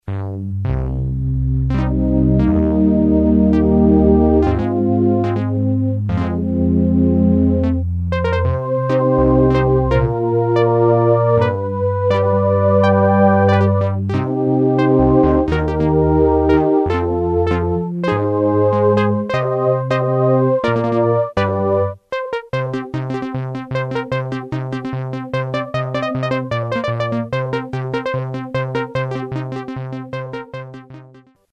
synth bass, a couple of